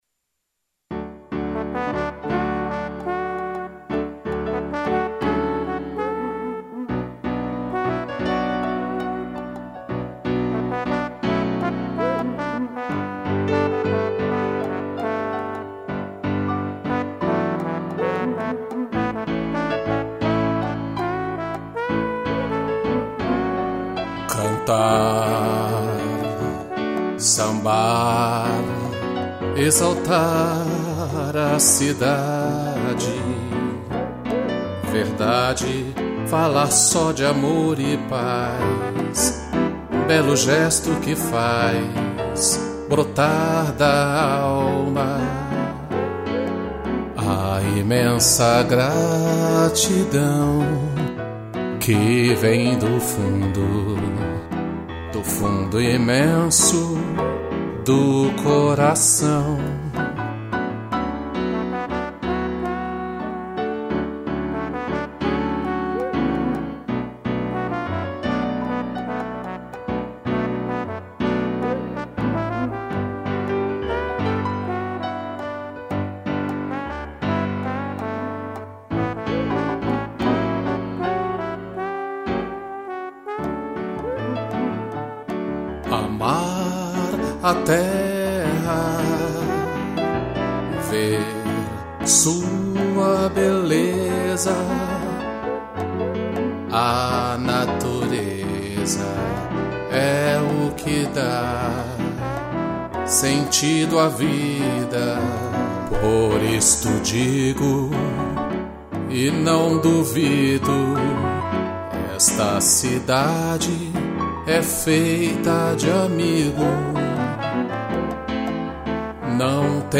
voz
2 pianos, trombone, cuíca e tamborim